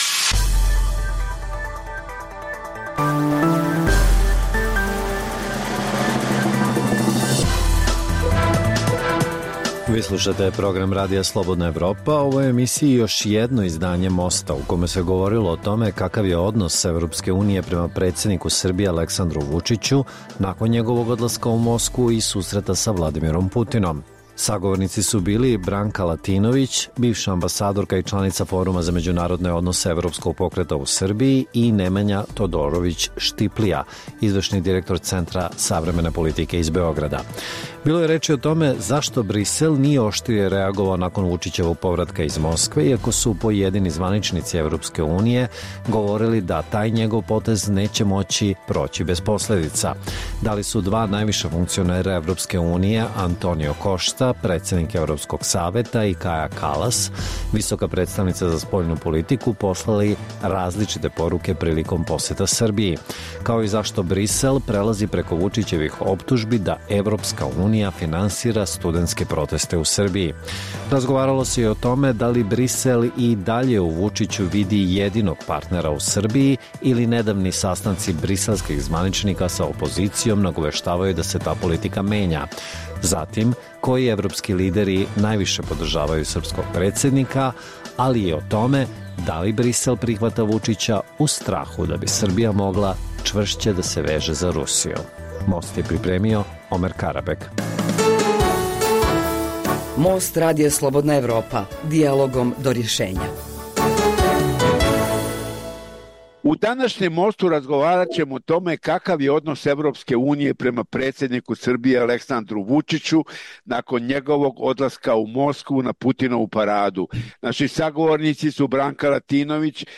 Dijaloška emisija o politici